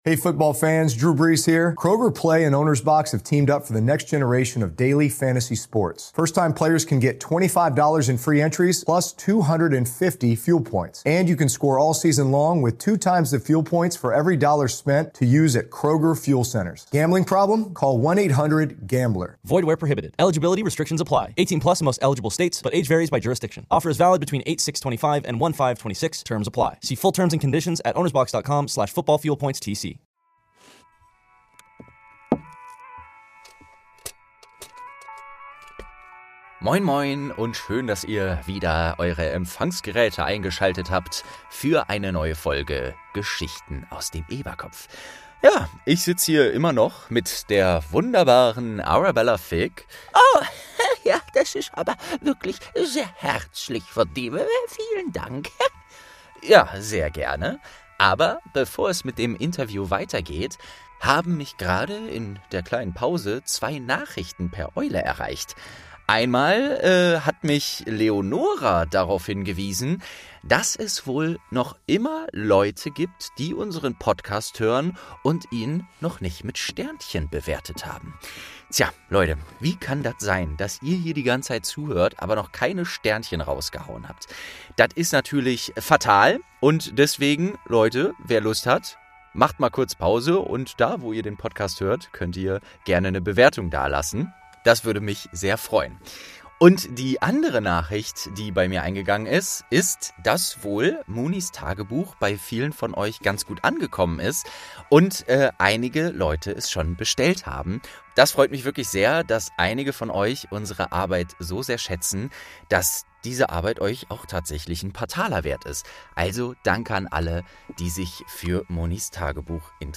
22. Auftrag vom Orden des Phönix | St. 2 ~ Geschichten aus dem Eberkopf - Ein Harry Potter Hörspiel-Podcast Podcast